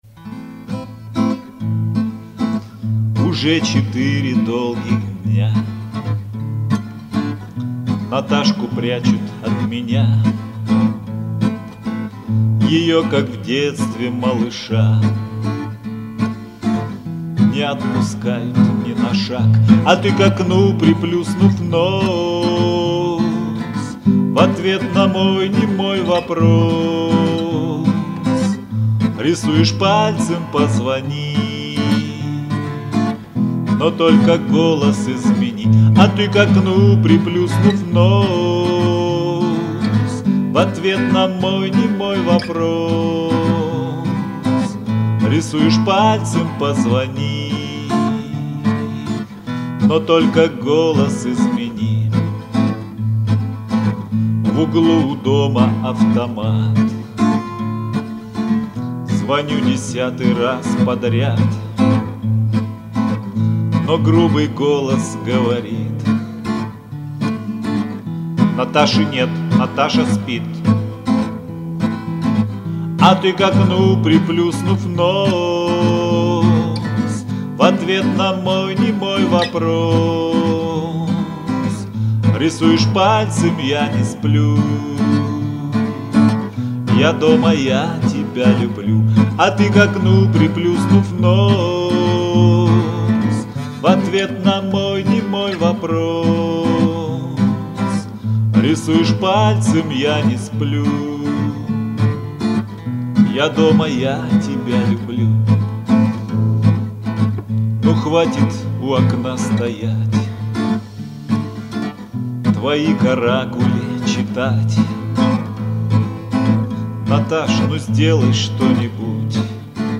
Армейские и дворовые песни под гитару
Дворовые